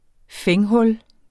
Udtale [ ˈfεŋ- ]